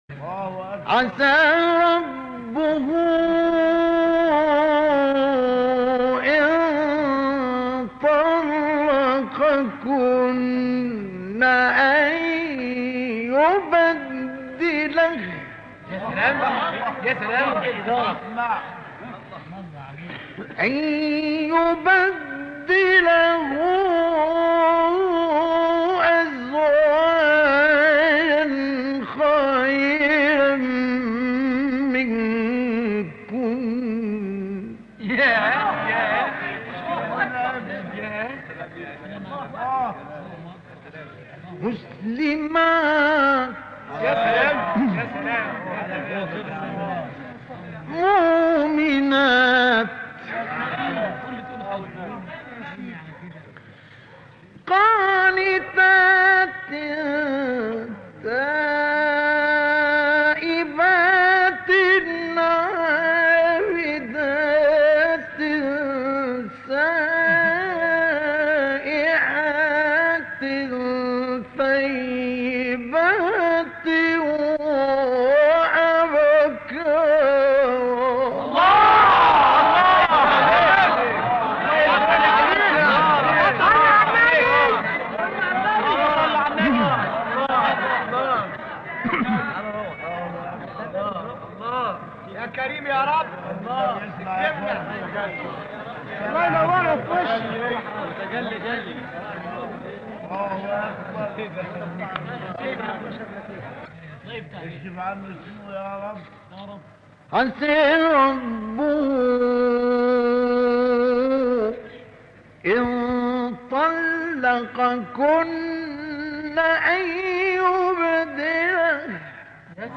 تلاوت سوره تحریم با صوت «مصطفی اسماعیل»
به گزارش خبرگزاری بین المللی قرآن(ایکنا)، تلاوت آیه 5 سوره مبارکه تحریم با صوت مصطفی اسماعیل، قاری برجسته مصری در کانال تلگرامی اکبرالقراء منتشر شده است.
این تلاوت در محفل تاریخی در سال 1968 میلادی اجرا شده است.